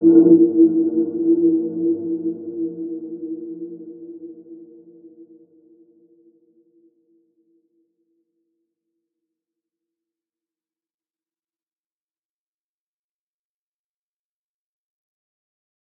Dark-Soft-Impact-E4-p.wav